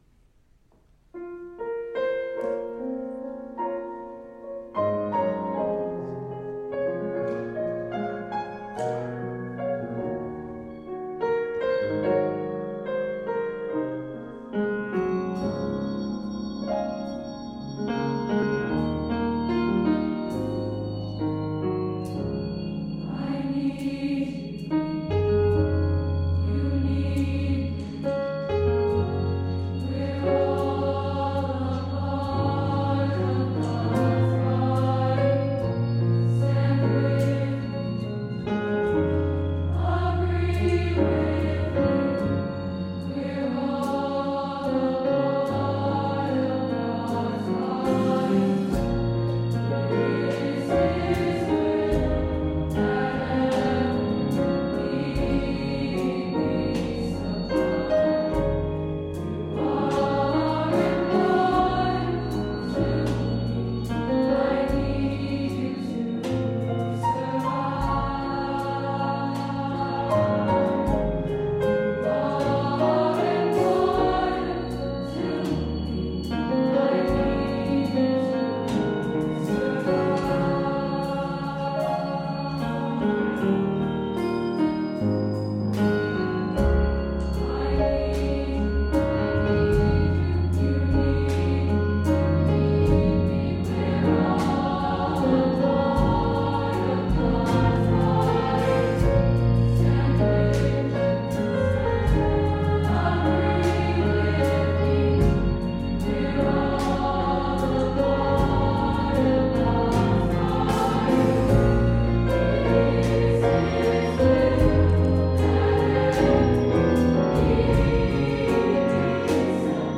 Voicing: SSA and Piano